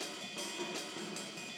RIDE_LOOP_14.wav